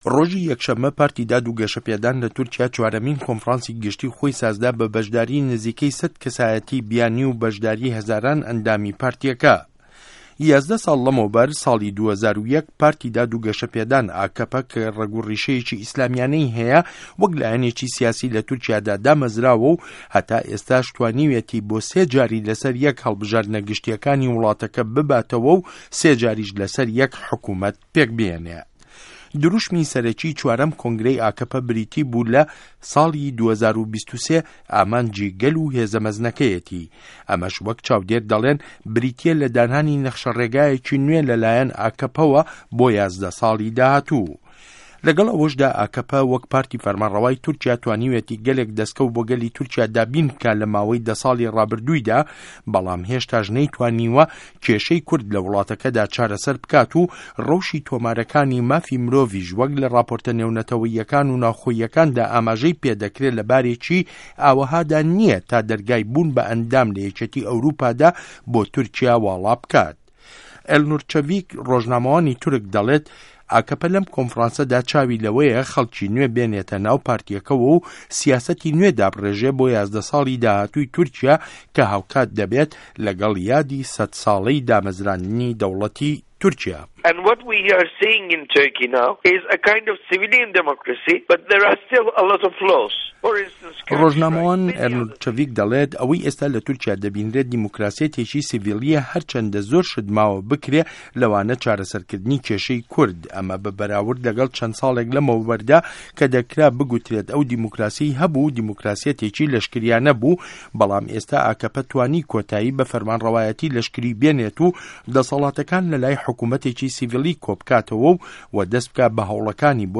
ڕاپۆرت له‌سه‌ر لێدوانه‌کانی ڕۆژنامه‌وان ئێلنور چه‌ڤیک